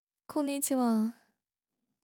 Fish Speech：無料で使える音声AIで「こんにちは！」と言ってもらった